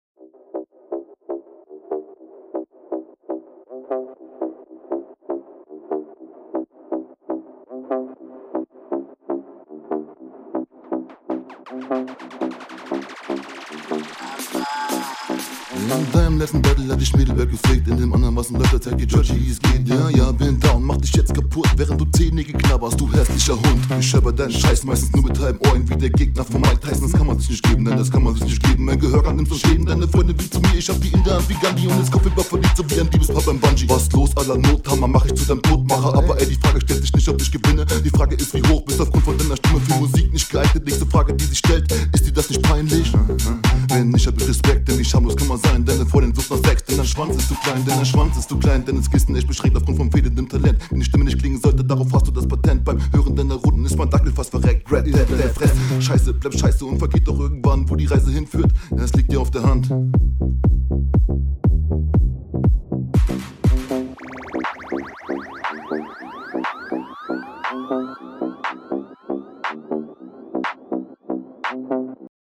Aussprache nicht so deutlich. Geht aber mies nach vorne.
Ist flowlich gar nicht so bad, klingt aber teilweise bisschen gehetzt.